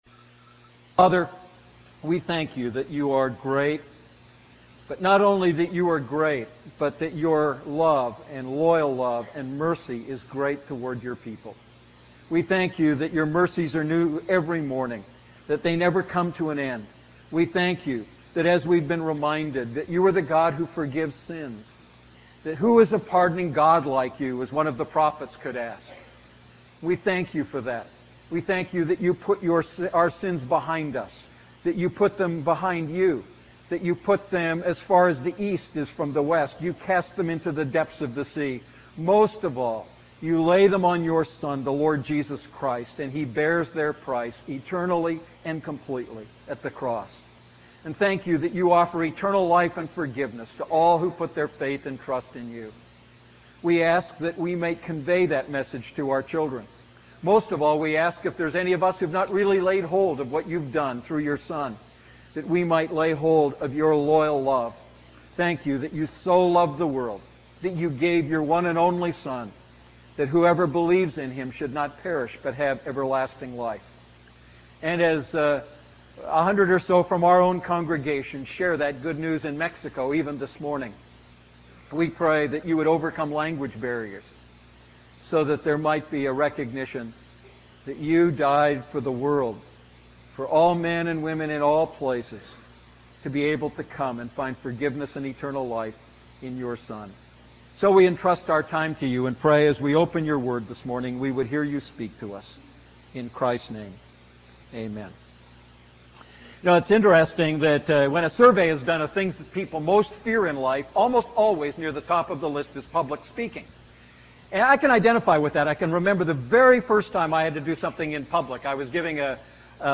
A message from the series "Family Matters."